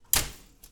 Close Oven Door Sound
household